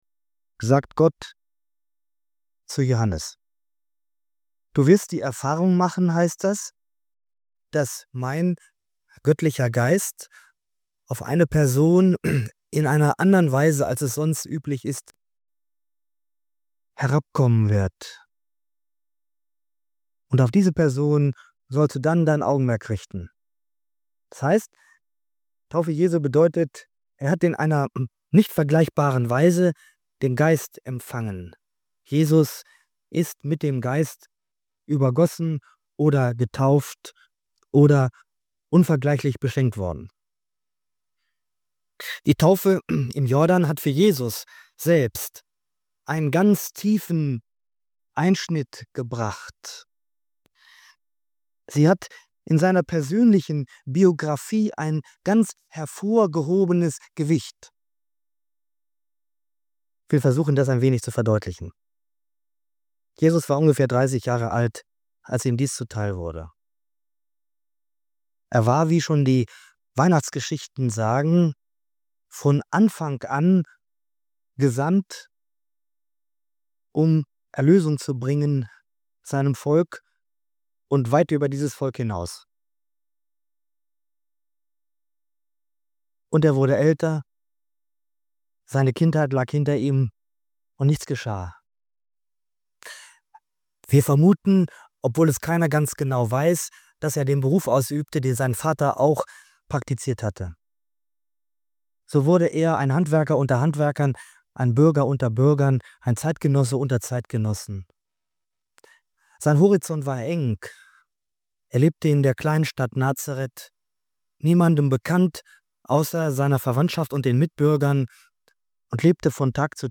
Diese Predigt entfaltet die tiefe Bedeutung der Taufe Jesu im Jordan als Wendepunkt seines Lebens und als Schlüssel zum Verständnis seines Wirkens.